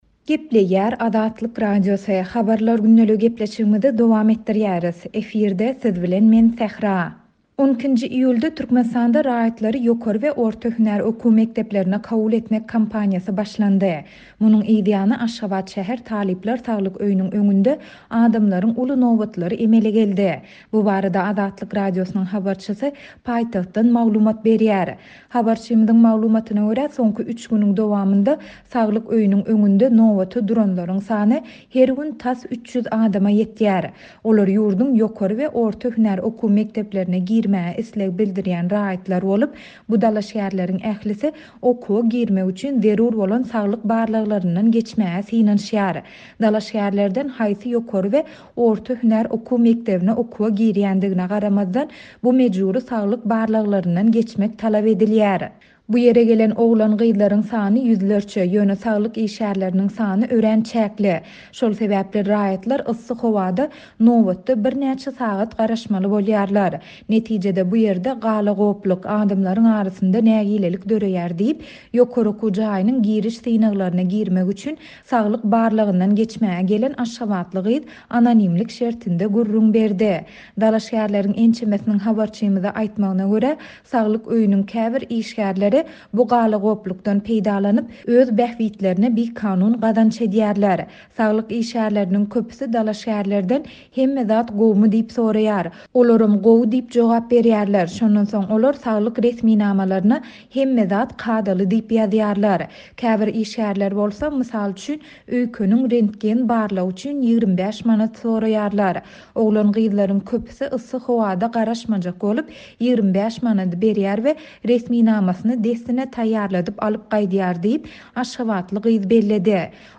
Munuň yzýany, Aşgabat şäher Talyplar Saglyk öýüniň öňünde adamlaryň uly nobatlary emele geldi. Bu barada Azatlyk Radiosynyň habarçysy paýtagtdan maglumat berýär